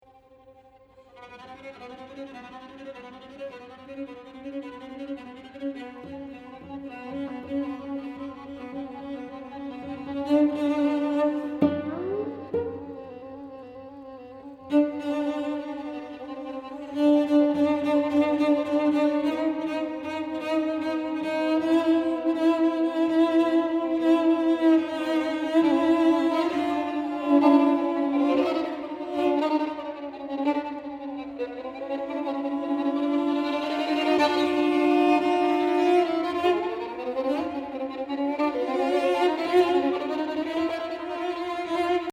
Violin & Cello